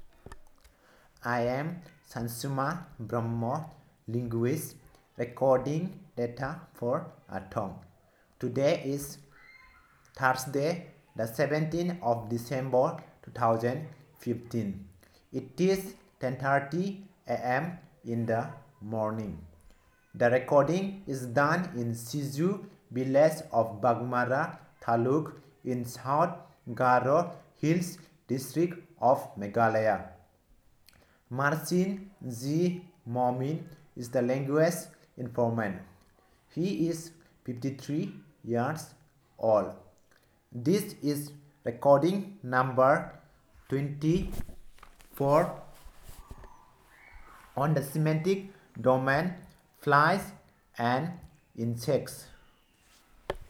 Elicitation of words about flies and insects